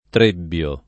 trebbiare v.; trebbio [